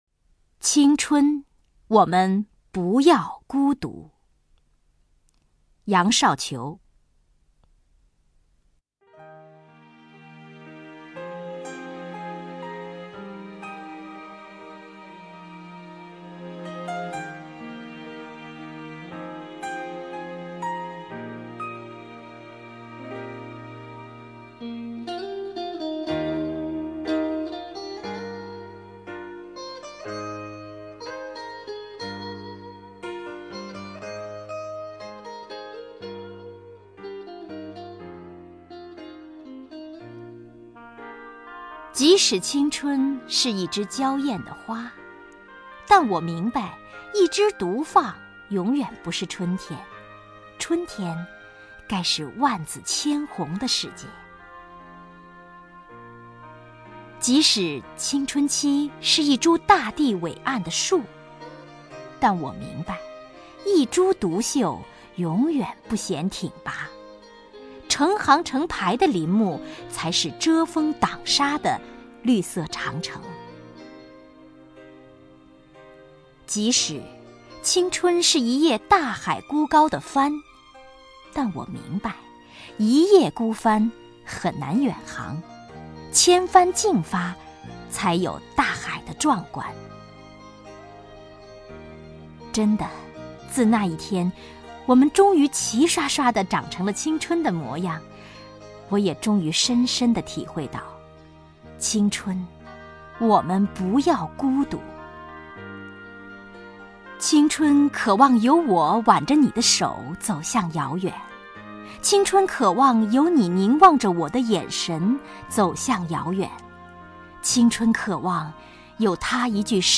首页 视听 名家朗诵欣赏 王雪纯
王雪纯朗诵：《青春，我们不要孤独》(杨绍球)